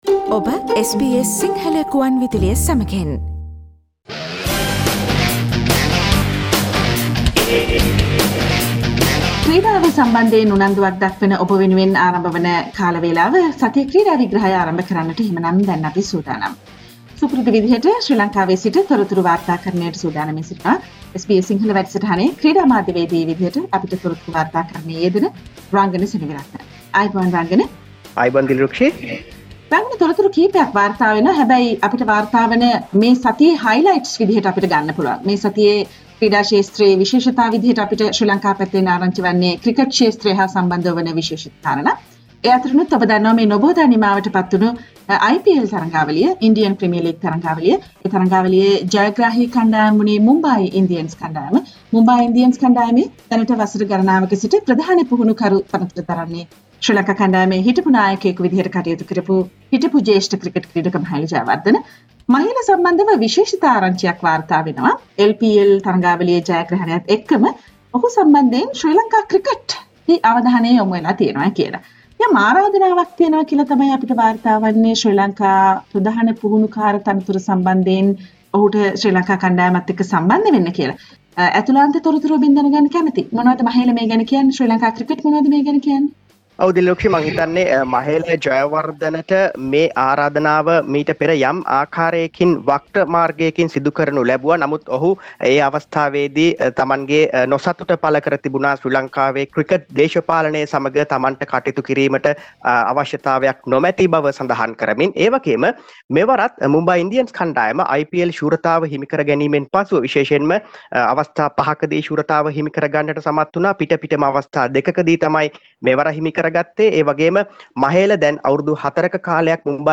Mahela says he will not accept Sri Lanka Cricket head coach post while match schedule has been fixed for LPL Source: SBS Sinhala radio